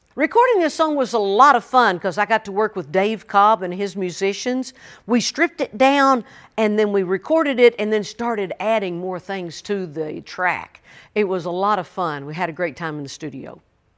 Reba McEntire talks about the recording process for her new song, "I Can't."